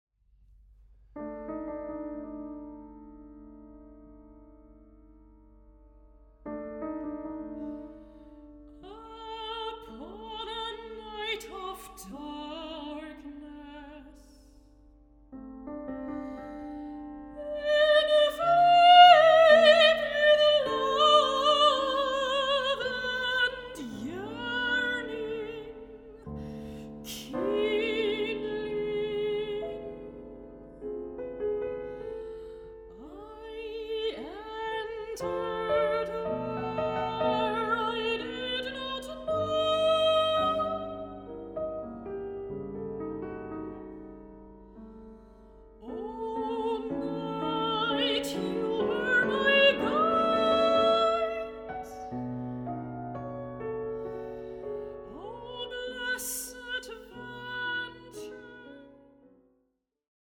Mezzo-soprano
piano
Recording: Mendelssohn-Saal, Gewandhaus Leipzig, 2025